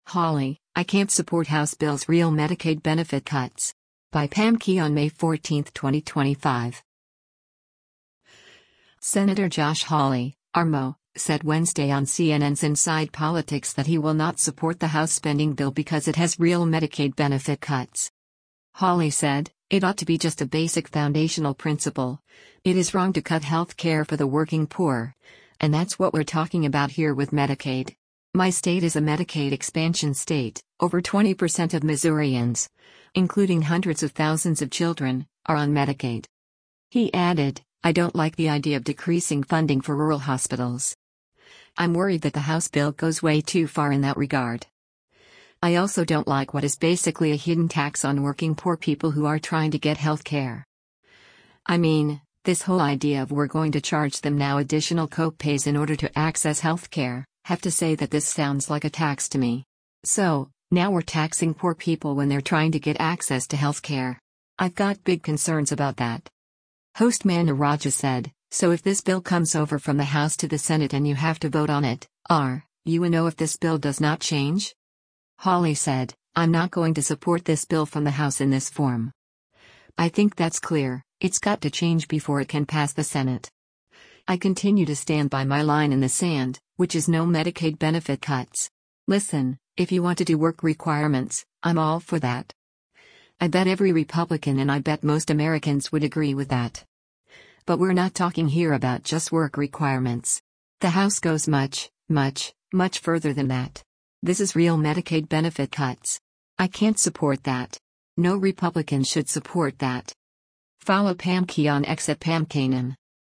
Senator Josh Hawley (R-MO) said Wednesday on CNN’s “Inside Politics” that he will not support the House spending bill because it has “real Medicaid benefit cuts.”
Host Manu Raju said, “So if this bill comes over from the House to the Senate and you have to vote on it, are, you a no if this bill does not change?”